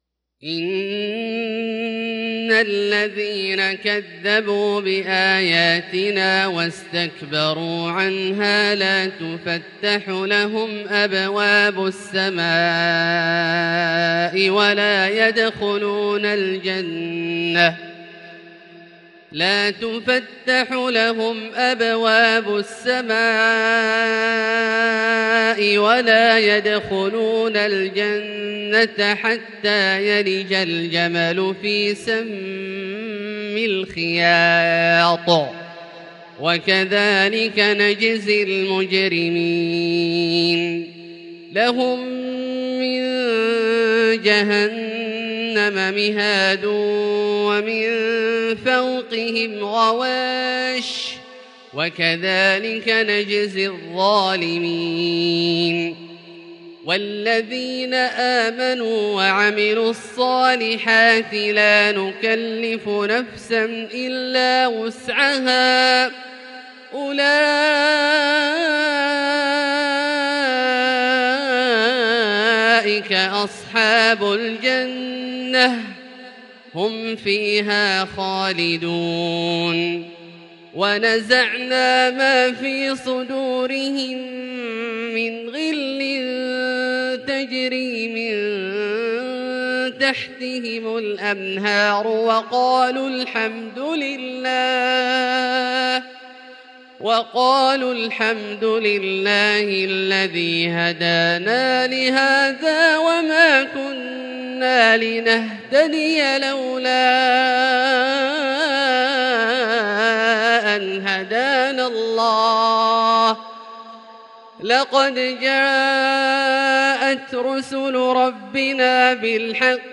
Fajr prayer from Surat 20/1/2021 > H 1442 > Prayers - Abdullah Al-Juhani Recitations